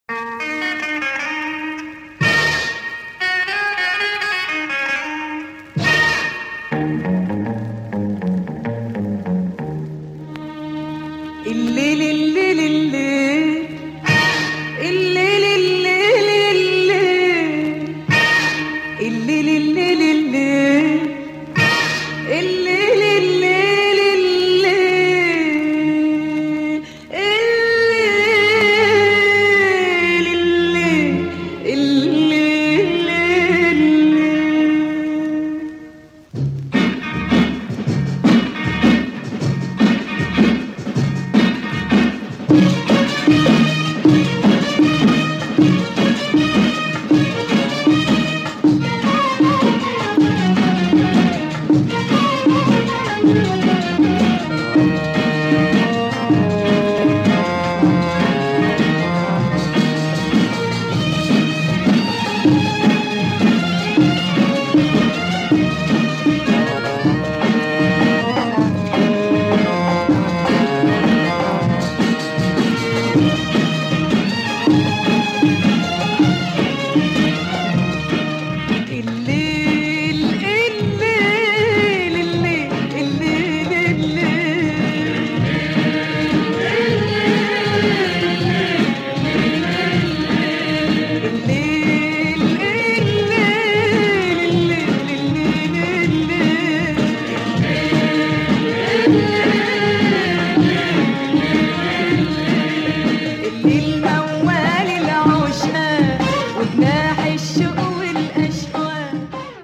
Groovy 70's oriental beat
Egyptian singer